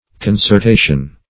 Search Result for " concertation" : The Collaborative International Dictionary of English v.0.48: Concertation \Con`cer*ta"tion\, n. [L. concertatio.] Strife; contention.